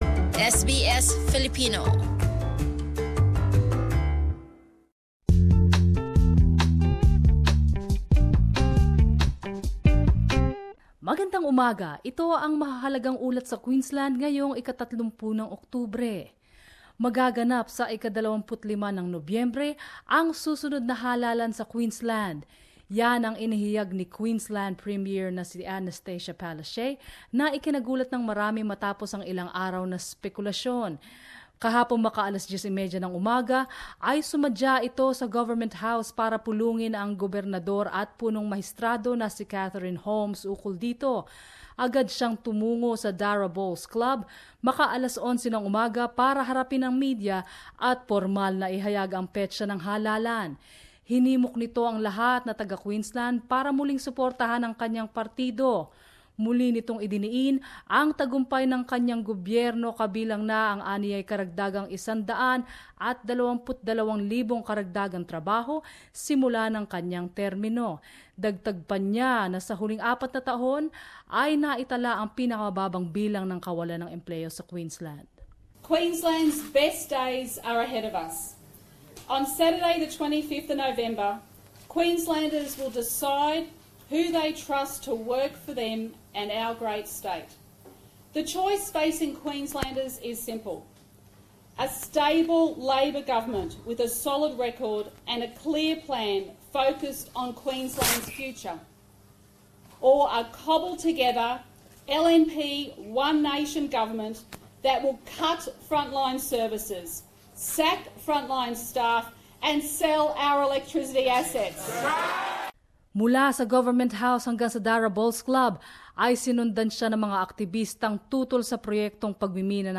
Inihayag ng Premyer ng Queensland ang mas maagang eleksyon na magaganap sa ika-25 ng Nobyembre habang nagsimula na ngayon ang pangangampanya. At iba mga balita mula Queensland